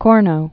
(kôrnō), Mount